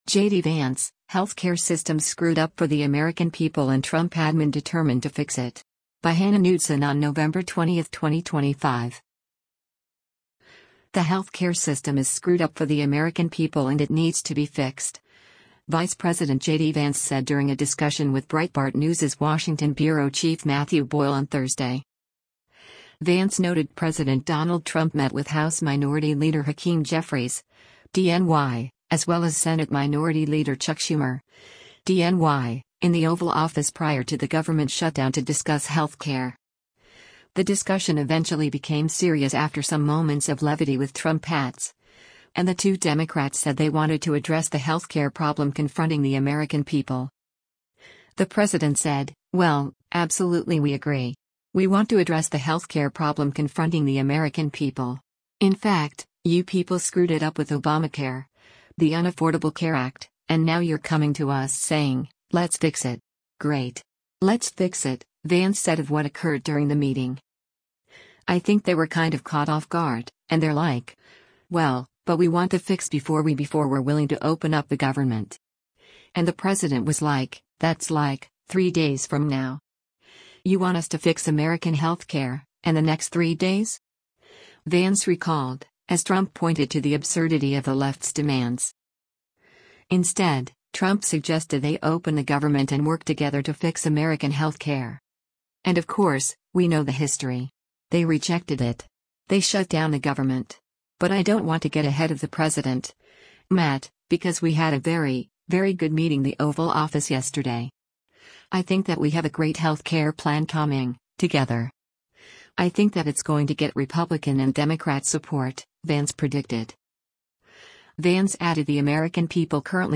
WASHINGTON, DC - NOVEMBER 20: U.S. Vice President JD Vance participates in a fireside chat